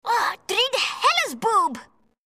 Voice3:..
Sex: Female